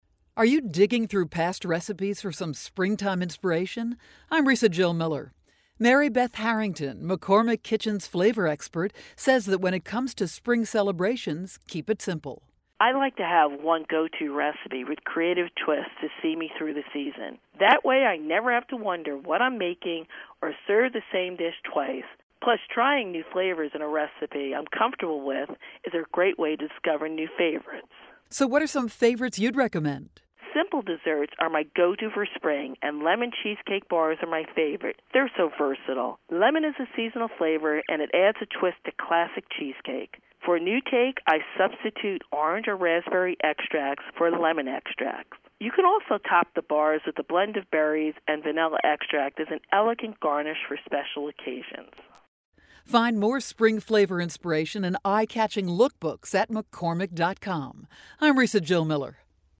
April 3, 2012Posted in: Audio News Release